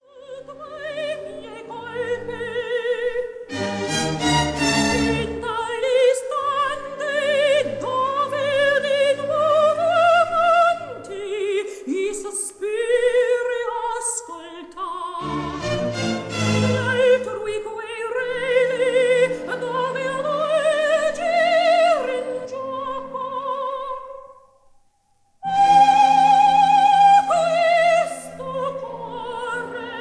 soprano
Recorded in Paris on 10 October 1955